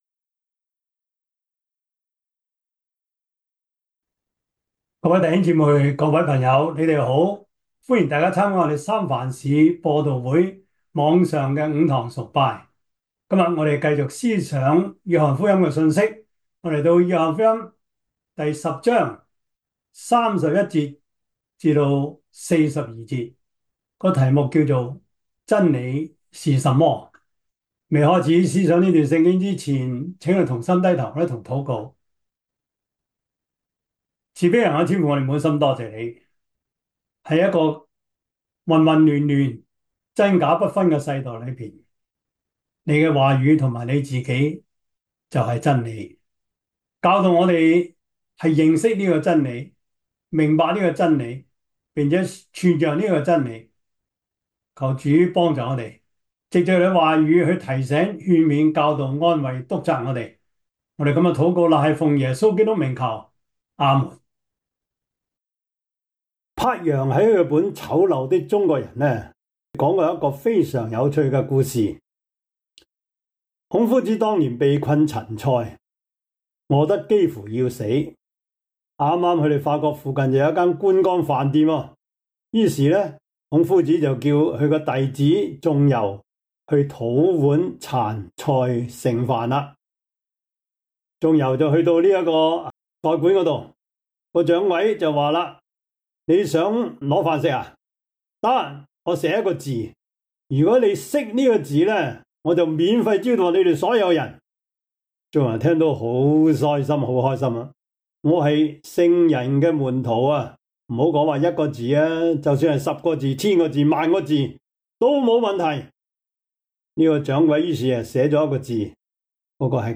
約翰福音 10:31-42 Service Type: 主日崇拜 約翰福音 10:31-42 Chinese Union Version
Topics: 主日證道 « 知行合一, 知易行難 使命人生 »